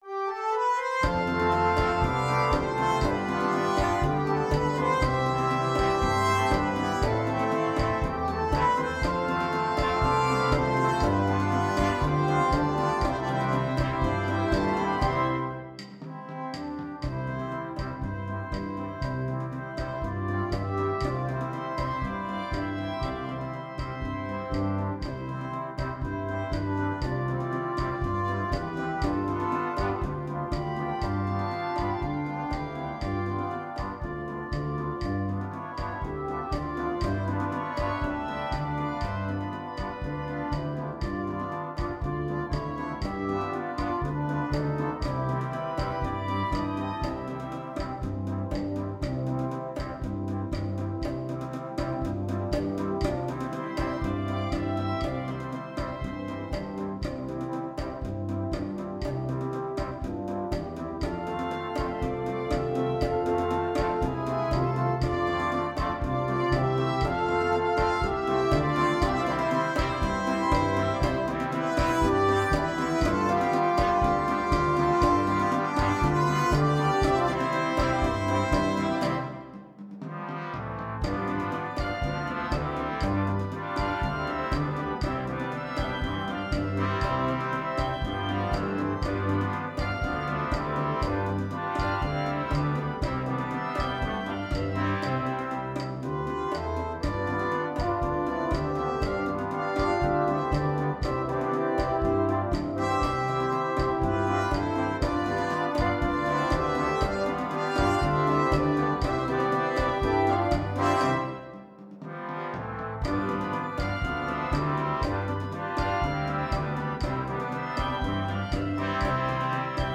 2. Egerlander-style band
Full Band
without solo instrument
Entertainment